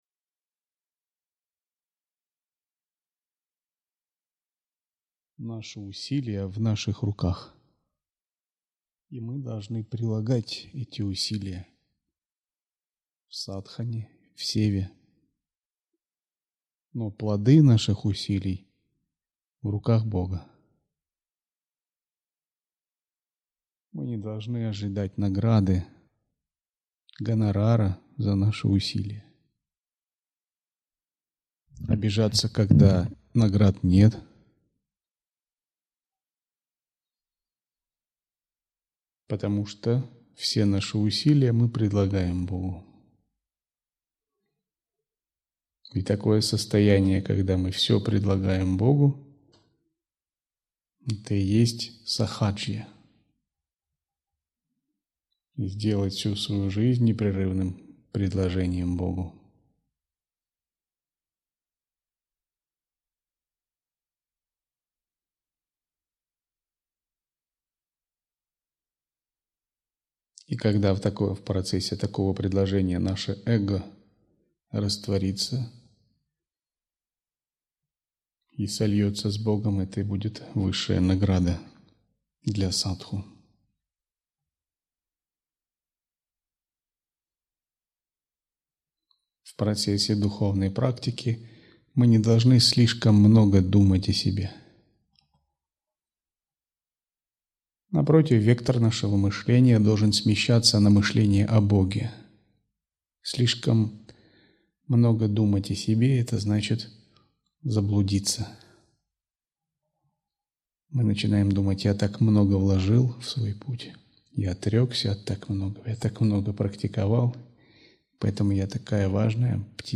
Аудиолекции